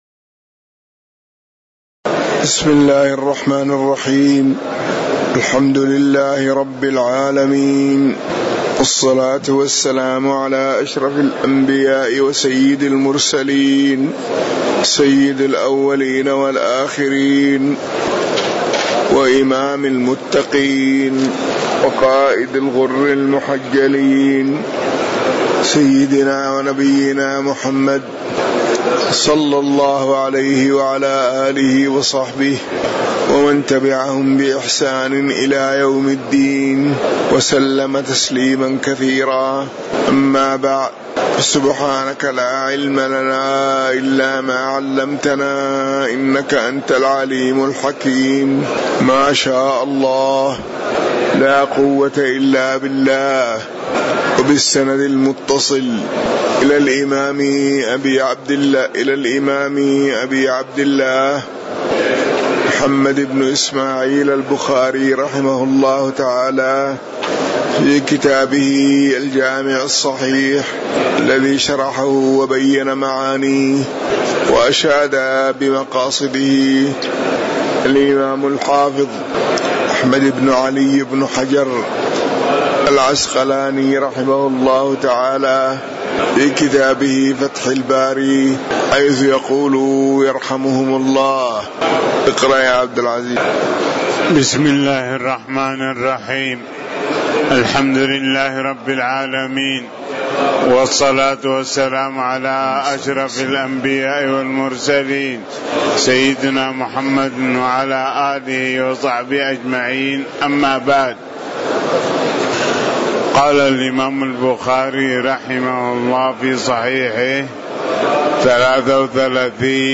تاريخ النشر ١٥ رمضان ١٤٤٠ هـ المكان: المسجد النبوي الشيخ